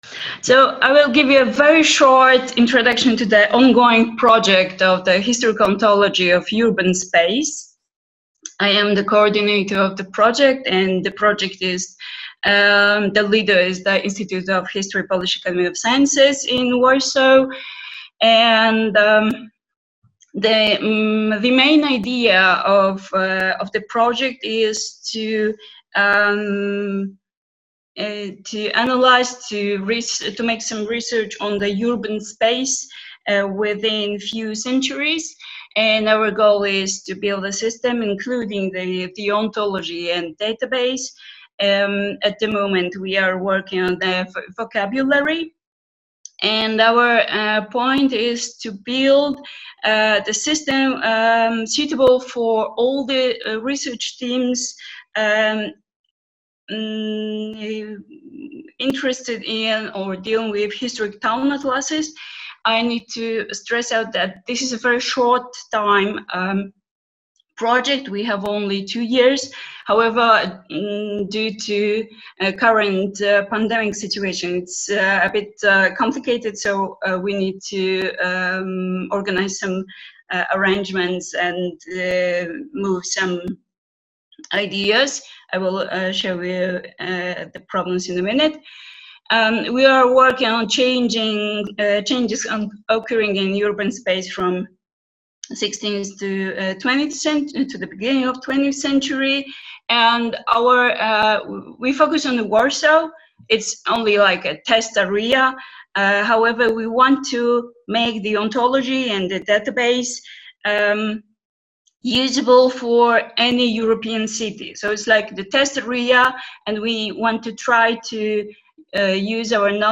Flash talk